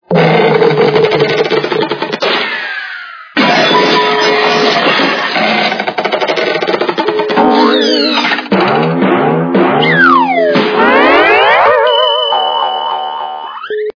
При прослушивании Звук приведения - для звука на СМС качество понижено и присутствуют гудки.
Звук Звук приведения - для звука на СМС